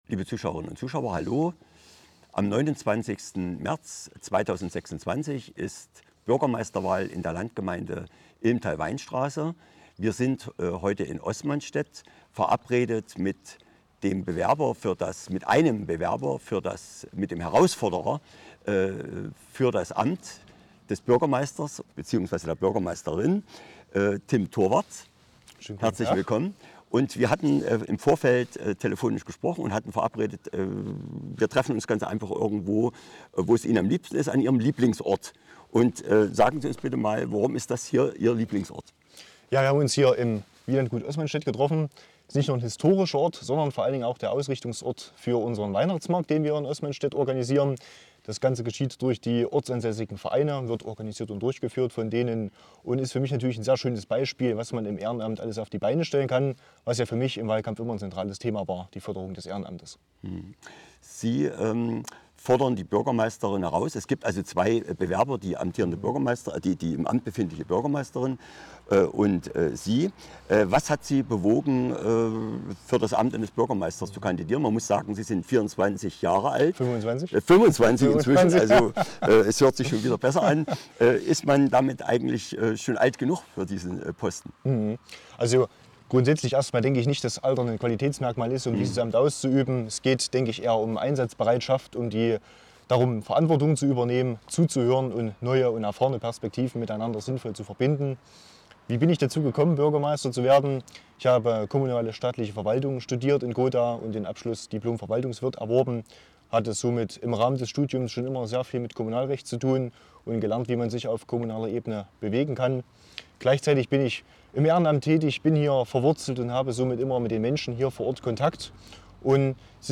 »Digitalisierung? Grundeinkommen!« Vortrag